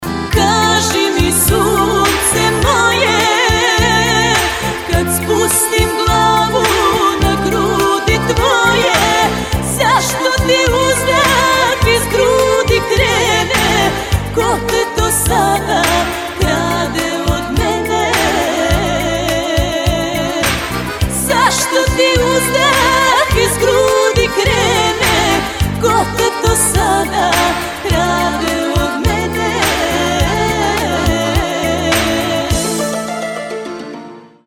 pesma koja je protkana zvcima sevdaha odnosno karasevdaha